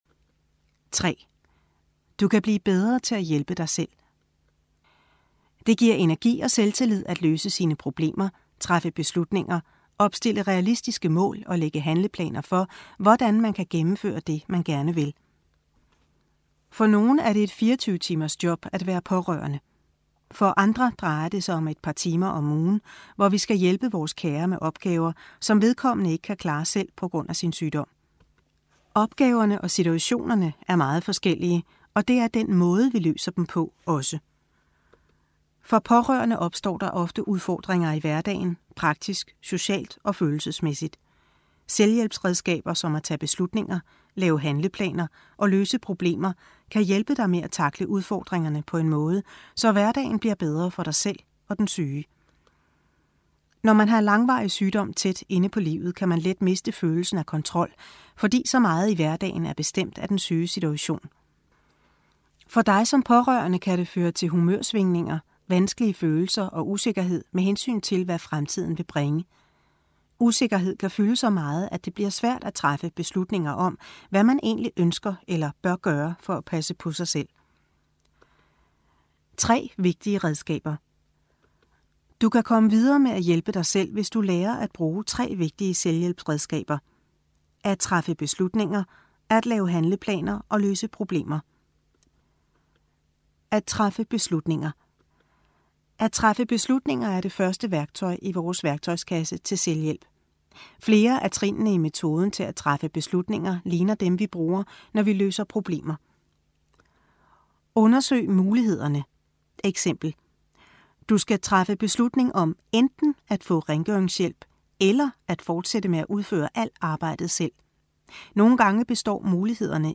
Genre: AudioBook.